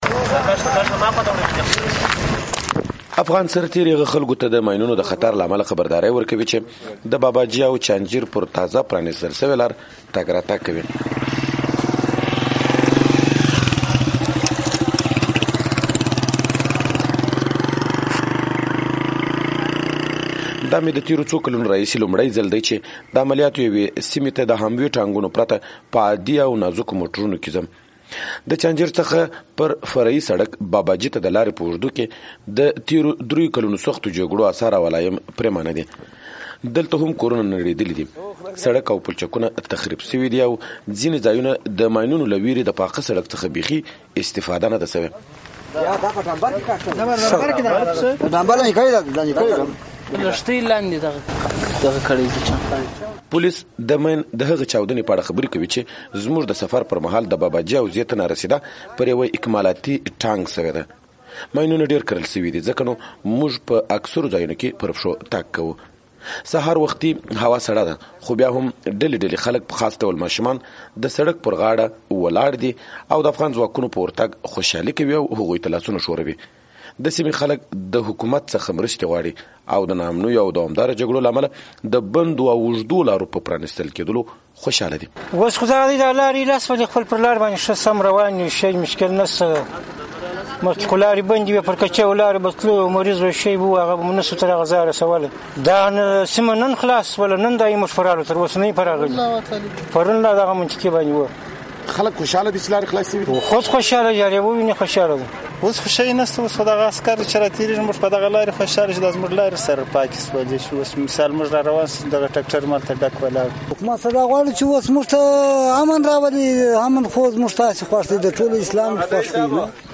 د هلمند راپور